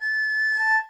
examples/harmonic_example.wav · ccmusic-database/erhu_playing_tech at main
harmonic_example.wav